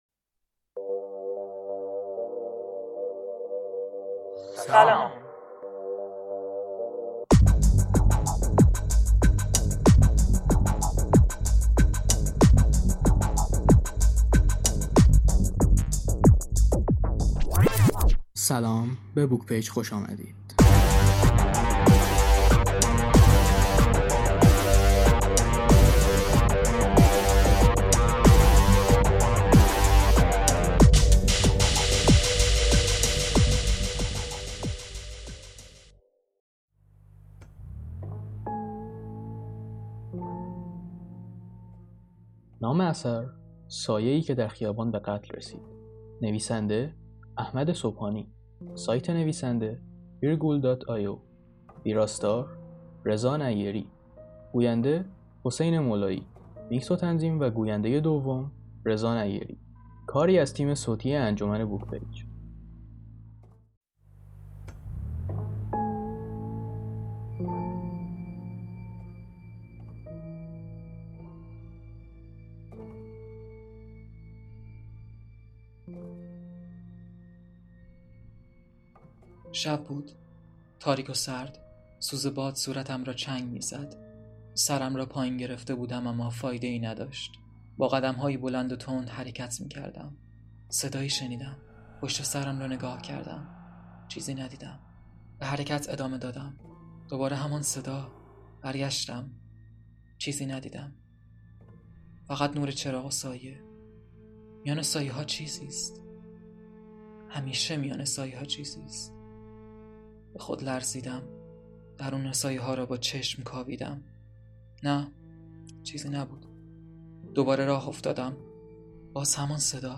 سایه‌ای در خیابان به قتل رسید | کتاب صوتی - پیشتازان کتاب